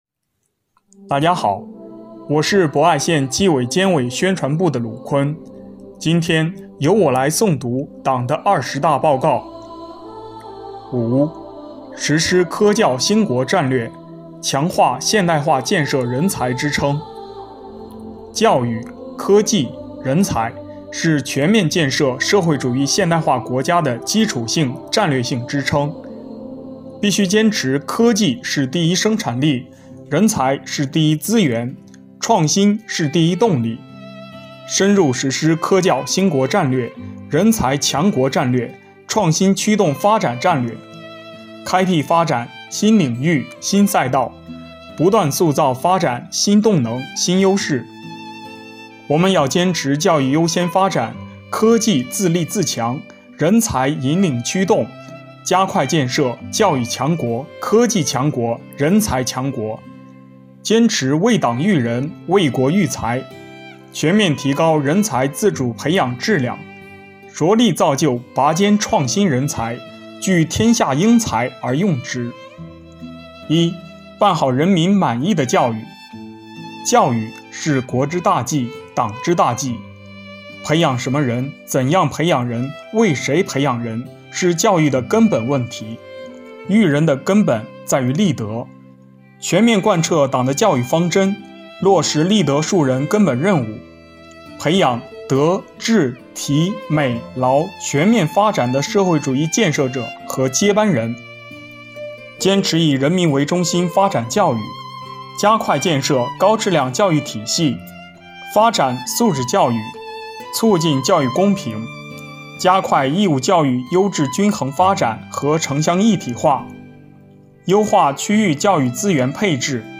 本期诵读人
诵读内容